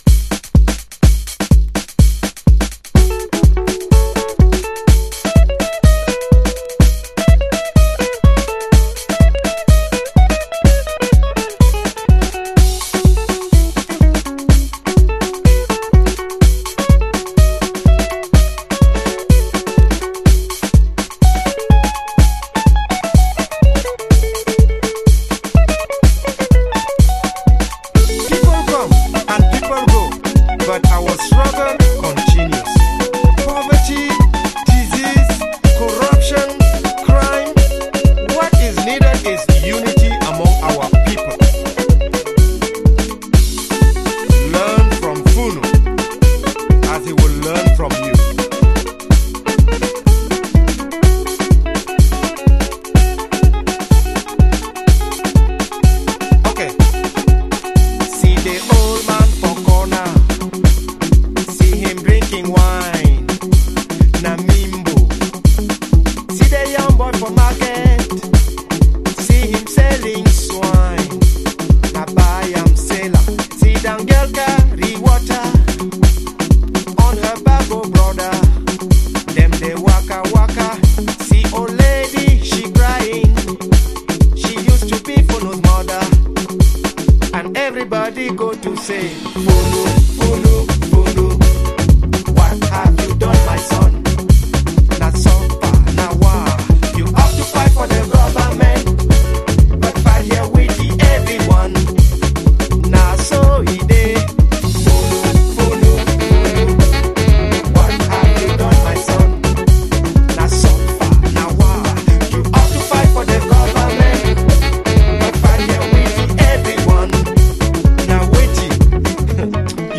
Early House / 90's Techno
熱く軽快に。AサイドはハイライフMIX、Bサイドはカメルーン・ギター・ダブ。